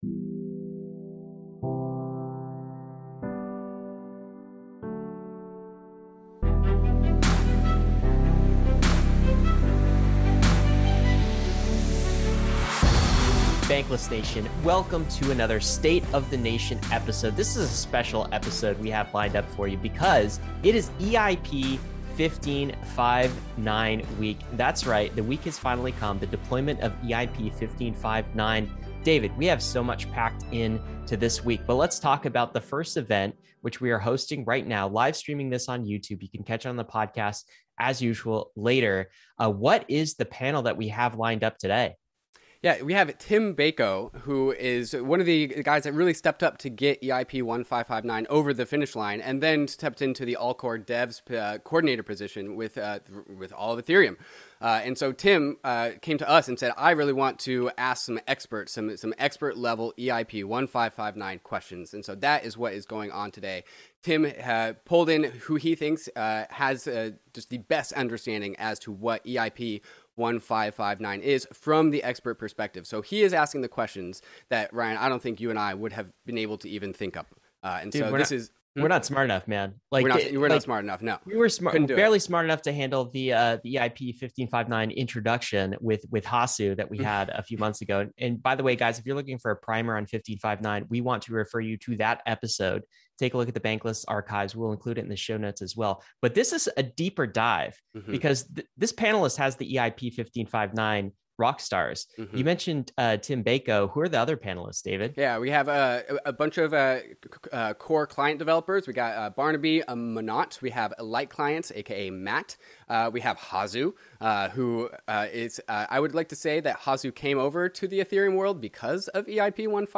They discuss the shifting dynamics between miners and users and the transformation of gas markets. Insights unfold on improving user experience, the complexities of miner extractable value (MEV), and ongoing challenges within the Ethereum ecosystem. This lively conversation highlights both skepticism and excitement as the community embraces change. 01:44:20 share Share public Creator website